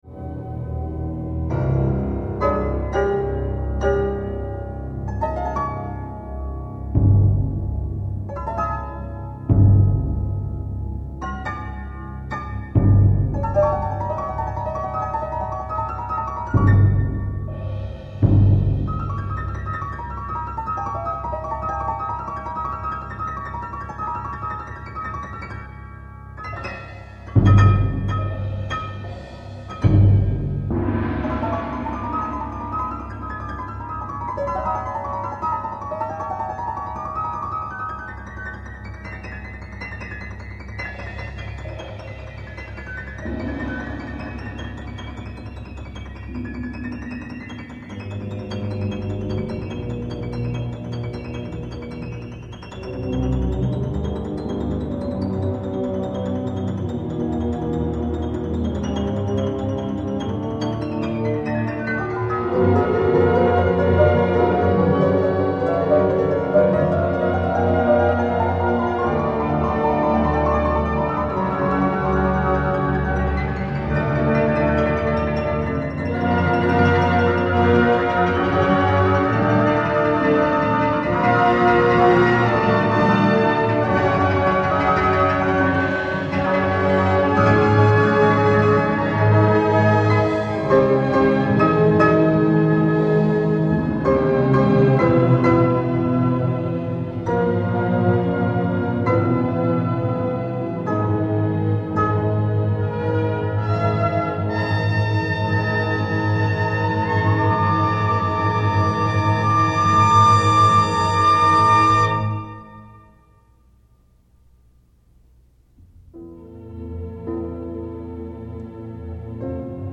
3-2-2-3, 4-2-3-1, Timp., Perc. (3), Pno, Str.